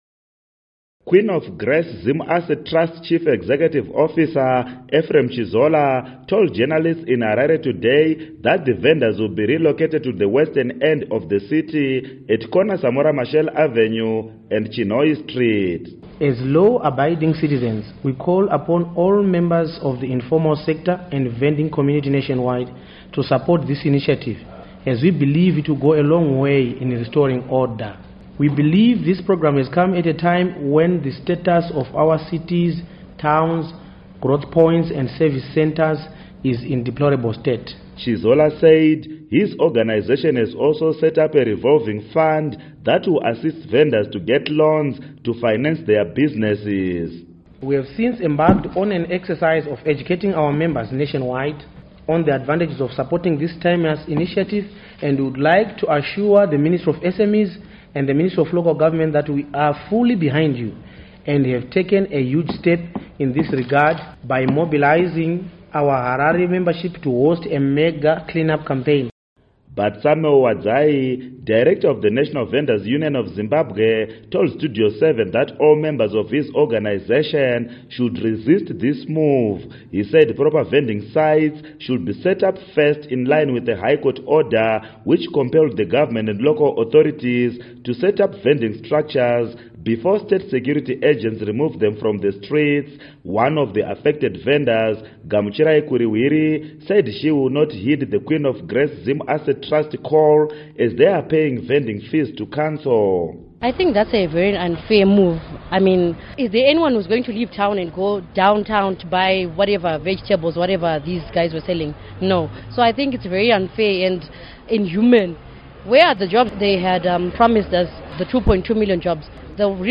Report on Attempts to Relocate Vendors